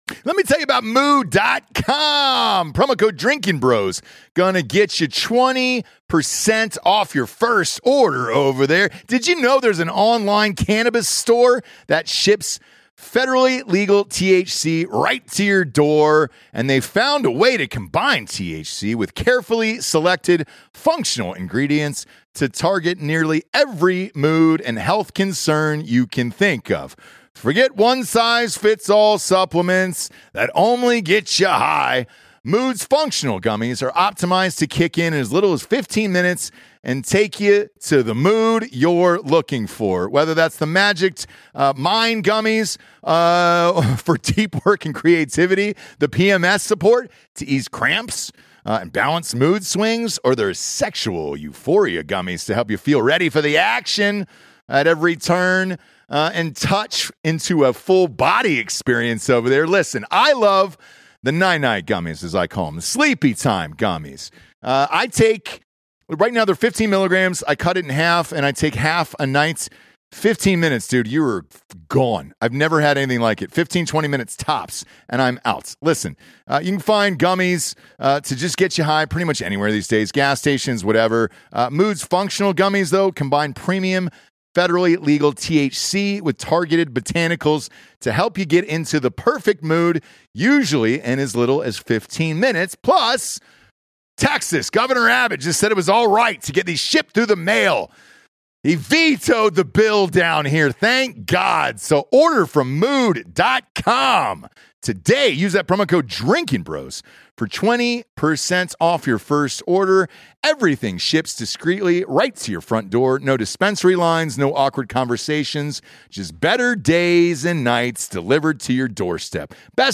Forgive the quality, it was a spur of the moment phone call.